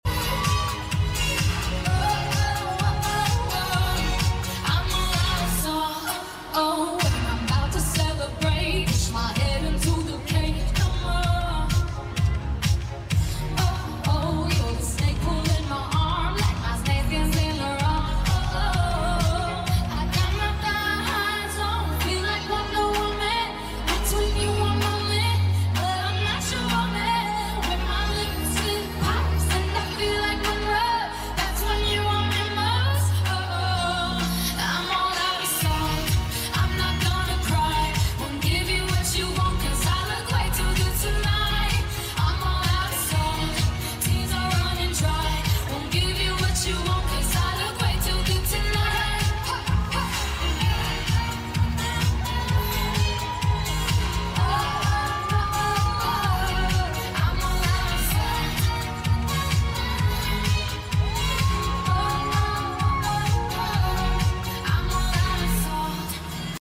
sounds like the the official audio.